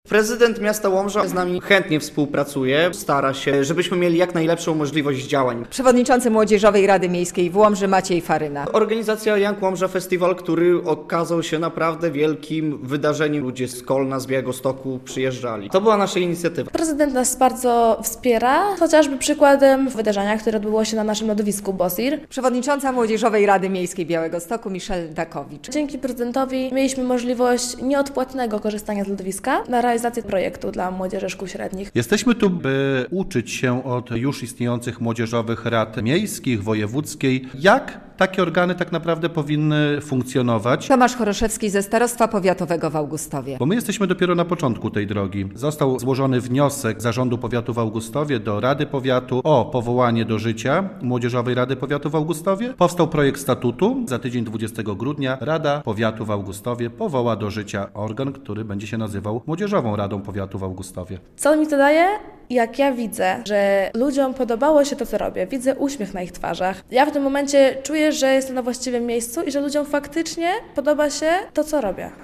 Działacze młodzieżowych rad spotkali się w Białymstoku - relacja